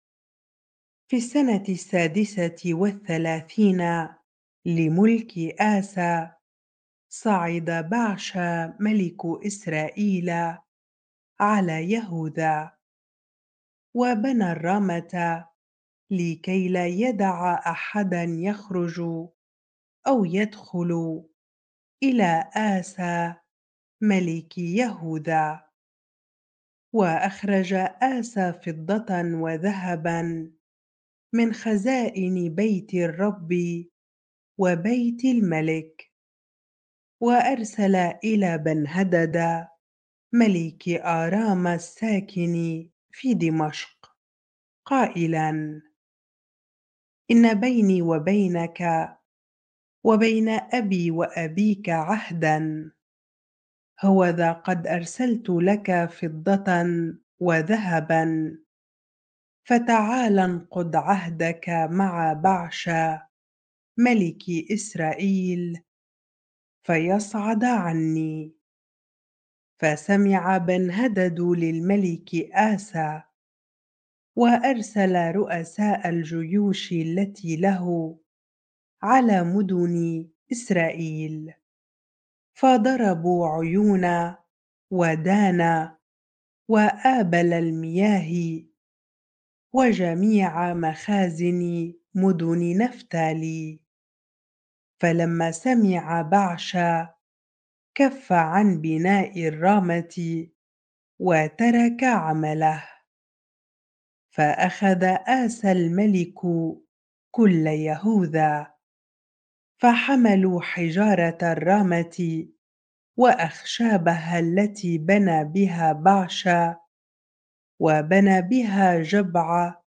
bible-reading-2 Chronicles 16 ar